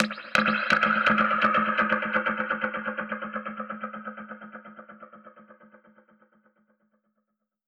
Index of /musicradar/dub-percussion-samples/125bpm
DPFX_PercHit_E_125-09.wav